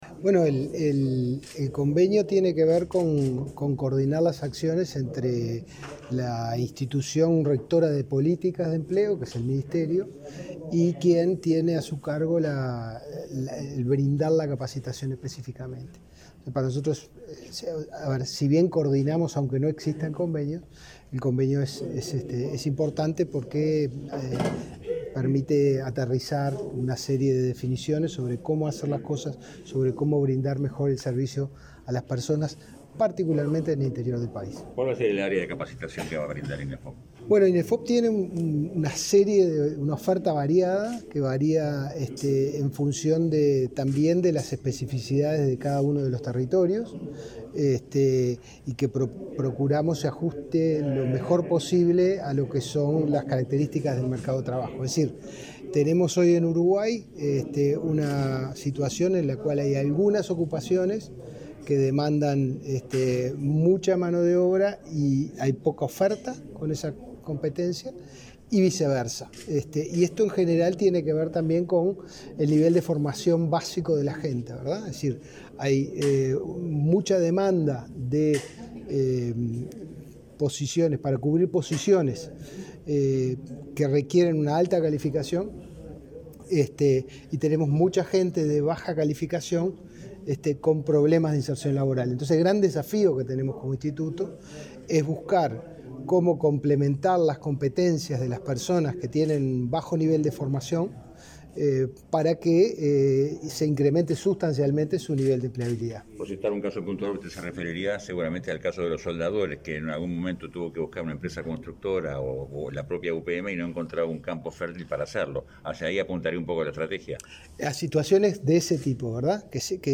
Declaraciones a la prensa del director general de Inefop, Pablo Darscht